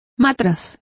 Complete with pronunciation of the translation of flask.